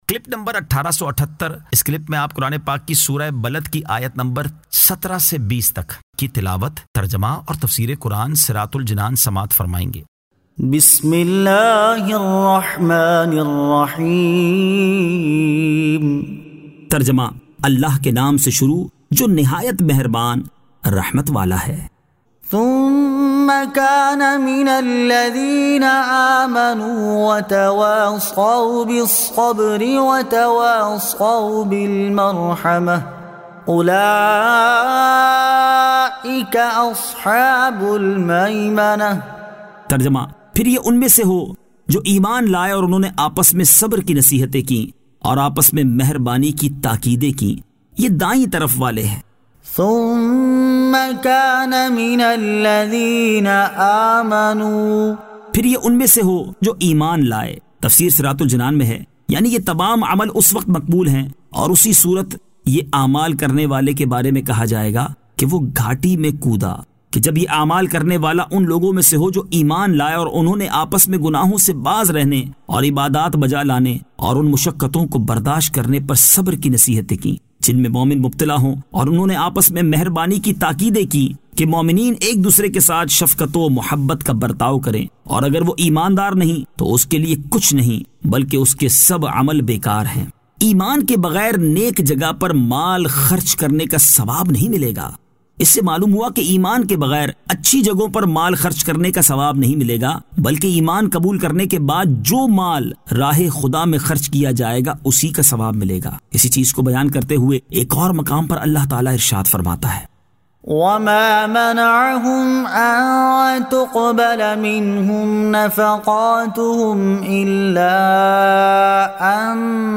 Surah Al-Balad 17 To 20 Tilawat , Tarjama , Tafseer